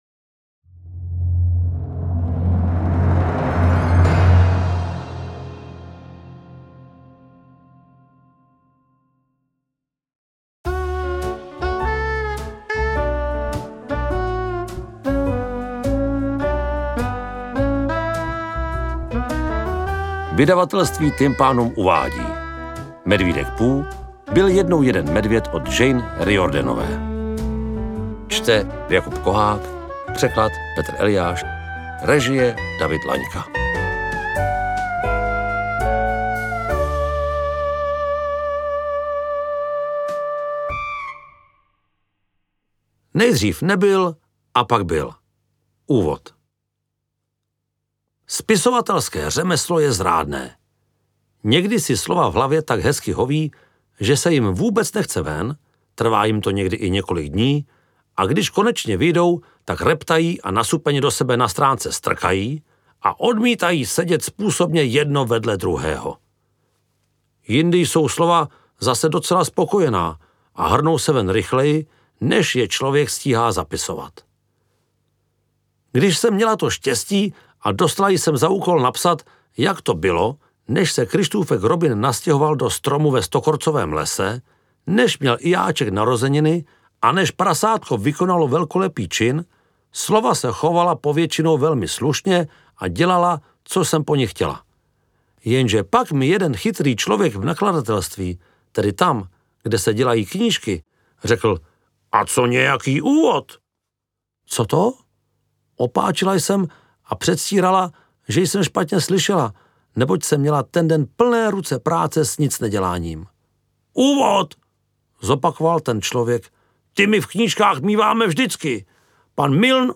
Interpret:  Jakub Kohák
AudioKniha ke stažení, 11 x mp3, délka 2 hod. 14 min., velikost 306,5 MB, česky